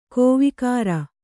♪ kōvikāra